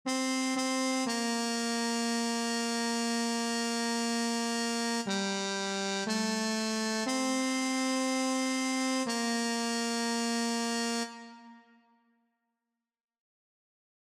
Key written in: B♭ Major
Type: Barbershop
Each recording below is single part only.